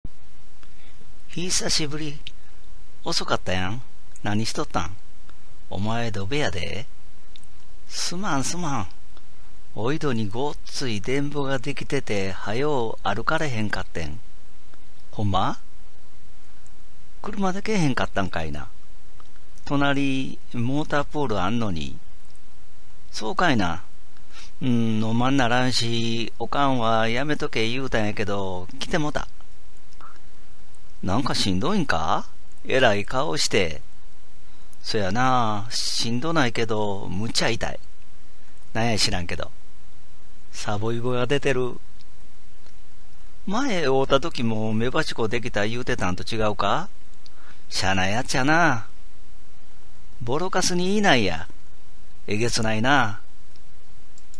私は河内育ちの大阪弁を話しますが、聞いて分かりますか
このために、今の大阪弁と河内弁混じりの「はなし言葉」を音声記録しておいて、10年ほどたってから聞き直すのも面白いのではないかと考えて、すこしはずかしいとおもいながらも、録音を含む記事を書きました。
最初は、普段の会話を感じていただくために、友達同士の会話をシミュレートしました。